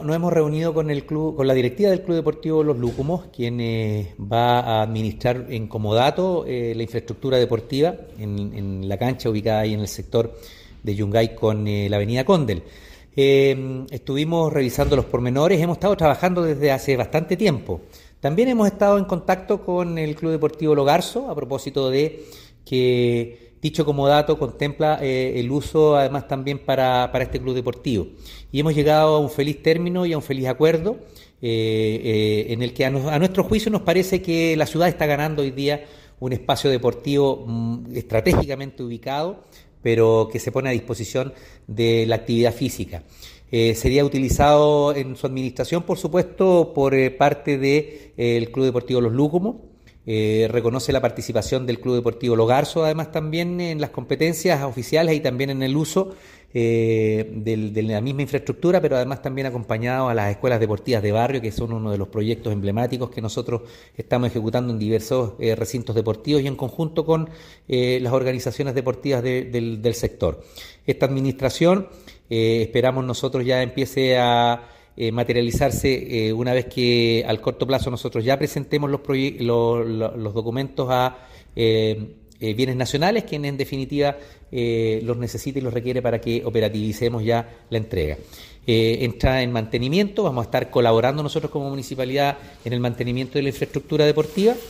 Alcalde-Oscar-Calderon-por-cancha-Los-Lucumos.mp3